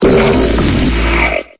Cri de Limonde dans Pokémon Épée et Bouclier.